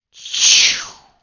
missileLaunch.wav